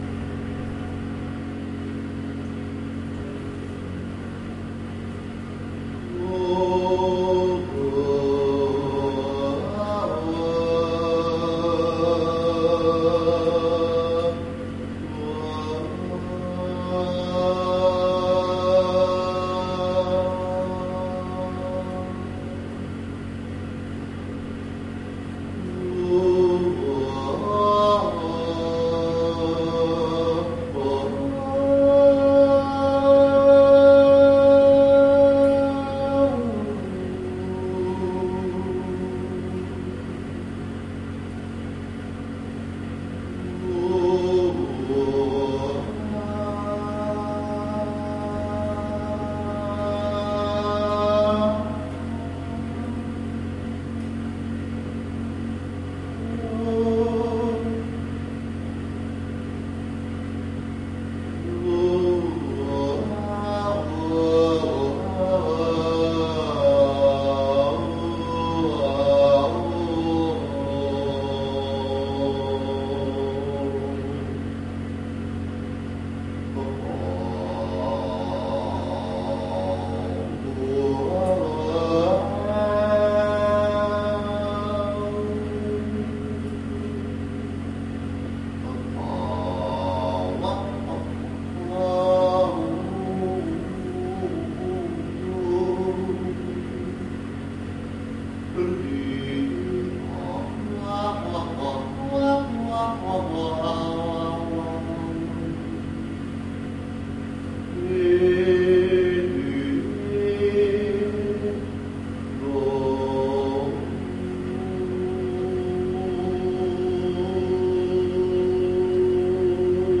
牛仔裤烘干机
描述：一条牛仔裤在烘干机里翻滚干燥。